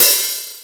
hat 28.wav